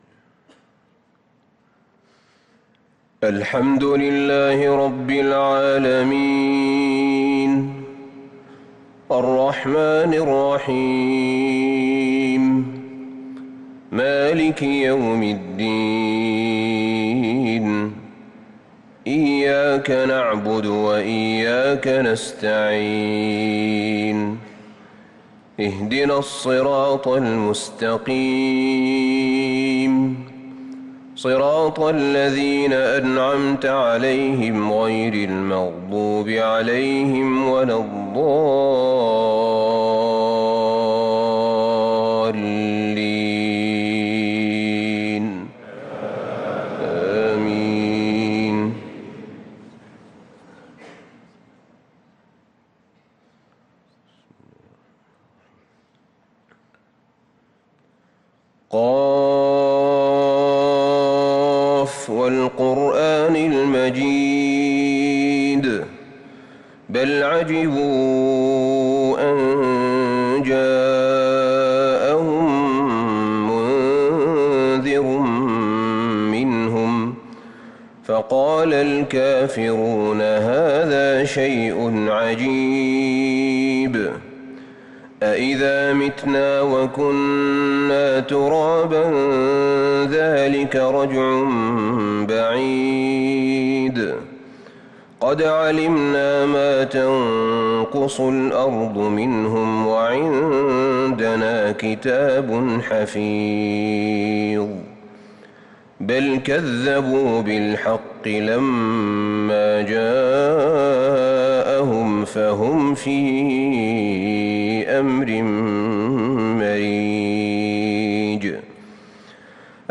صلاة الفجر للقارئ أحمد بن طالب حميد 19 ذو الحجة 1443 هـ